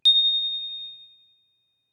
bell chime ding singlenote tone sound effect free sound royalty free Voices